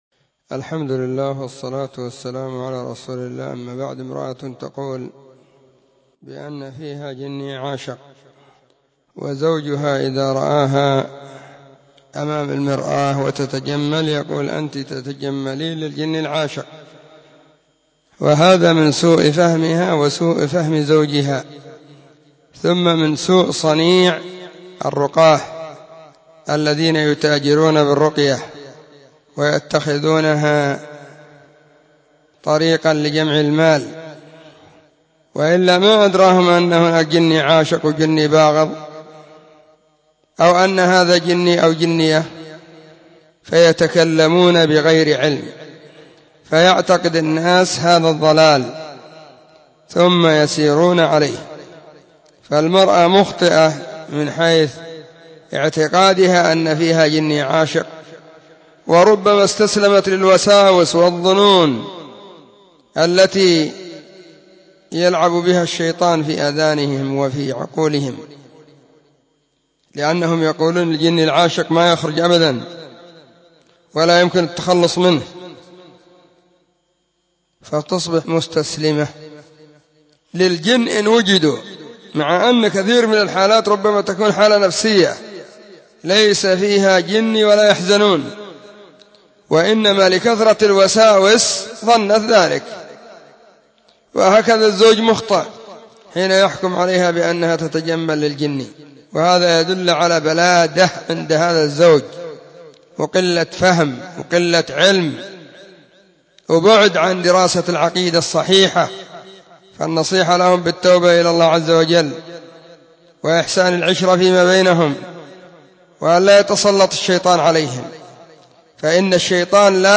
🔸🔹 سلسلة الفتاوى الصوتية المفردة 🔸🔹